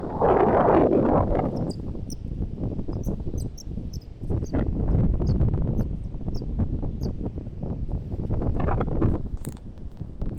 Playerito Rabadilla Blanca (Calidris fuscicollis)
Nombre en inglés: White-rumped Sandpiper
Localidad o área protegida: Reserva Natural Punta Rasa
Condición: Silvestre
Certeza: Observada, Vocalización Grabada